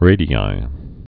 (rādē-ī)